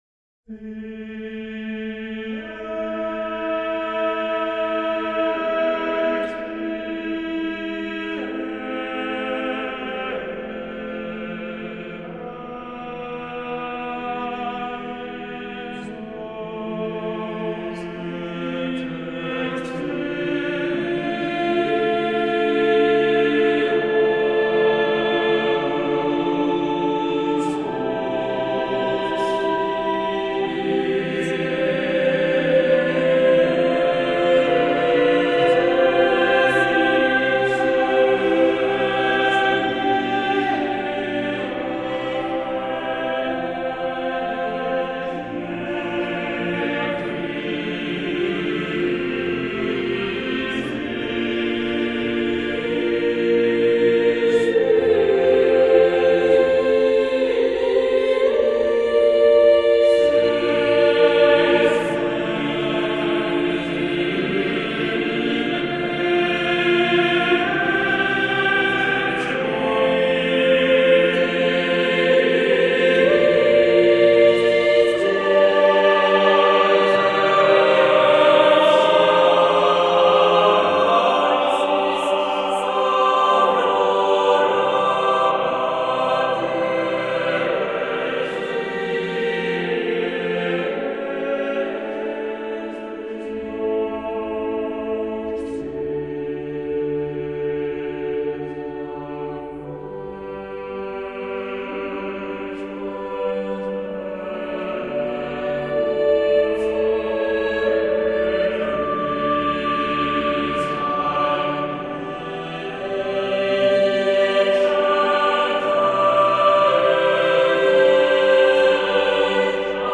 I told an AI to generate music to fit these words, which you can hear below.